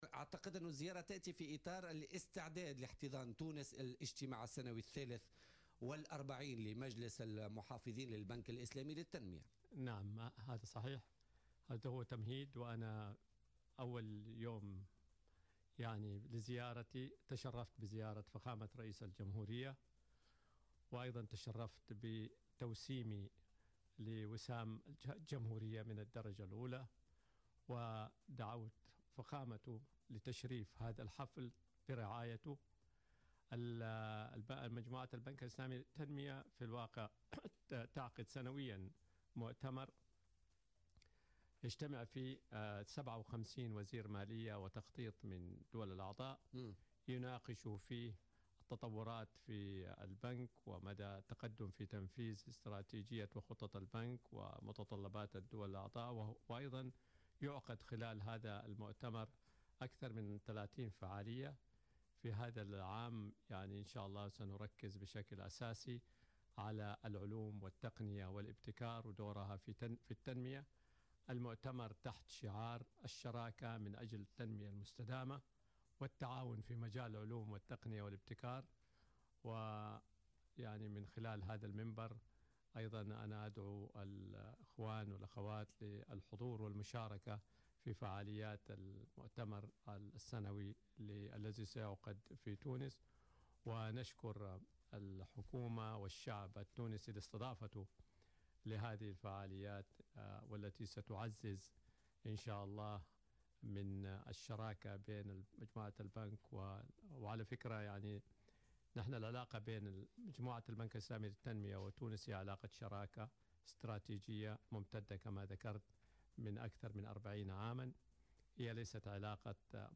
أكد رئيس مجموعة البنك الاسلامي للتنمية بندر الحجار ضيف بوليتيكا اليوم الجمعة 9 مارس 2018 أن زيارته إلى عدد من الولايات التونسي تأتي تمهيدا للمؤتمر الذي ستعقده المجموعة في تونس من 1 إلى 5 أفريل 2018 بعنوان الشراكة من أجل التنمية المستدامة مع التركيز على التعاون في مجال العلوم والتقنية والابتكار والاقتصاد التقني .